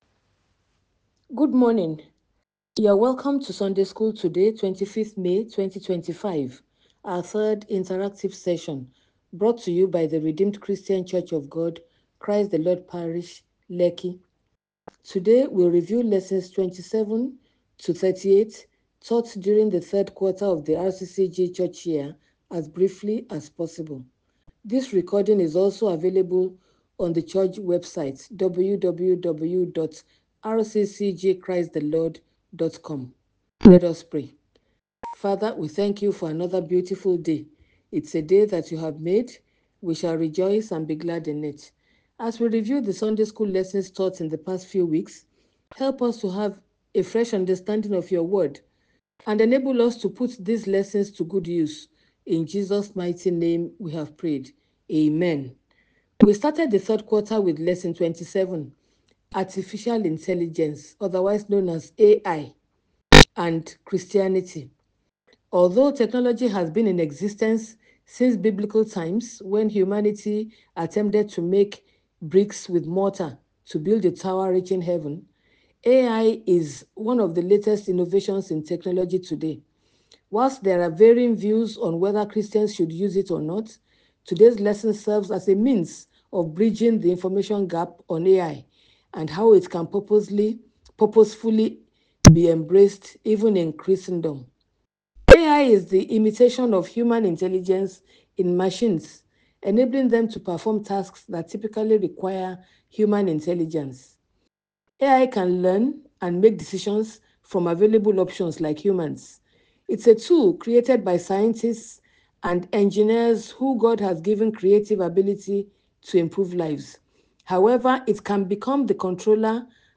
SUNDAY SCHOOL: Third Quarter Interactive Session and review of Lesson 27- Lesson 38.